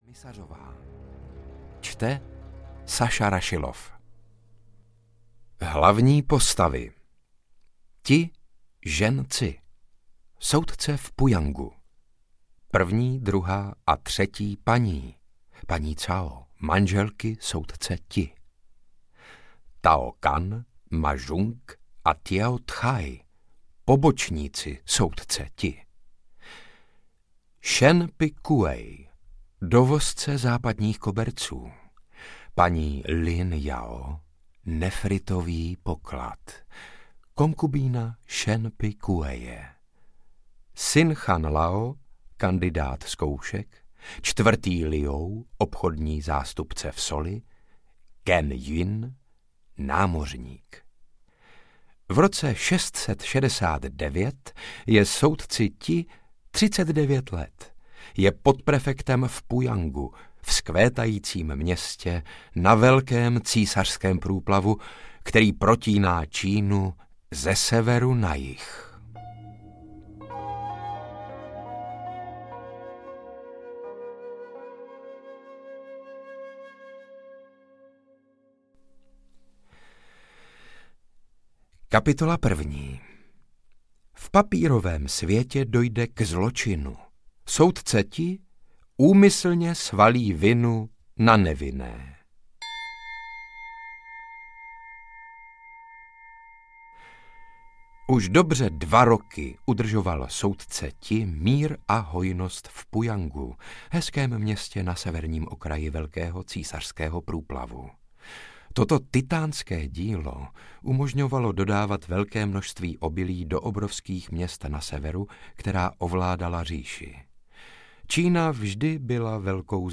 Ukázka z knihy
• InterpretSaša Rašilov